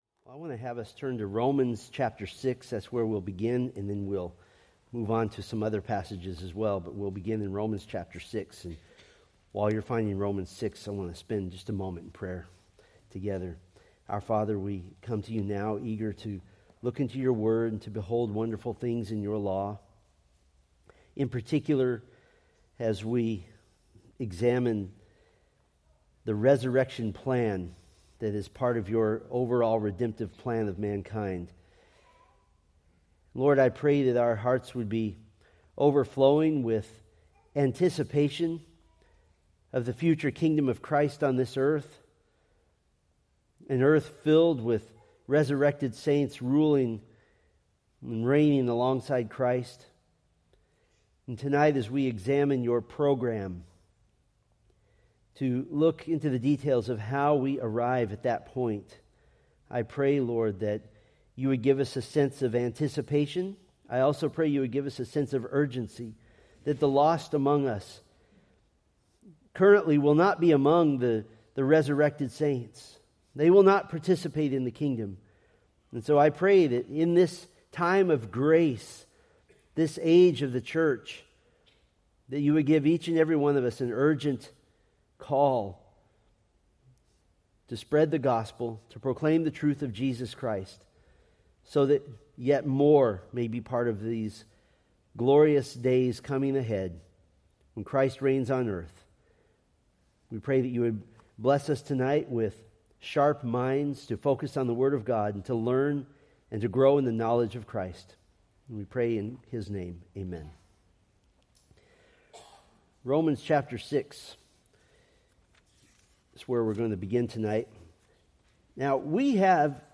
From the Millennium: New Testament Witnesses sermon series.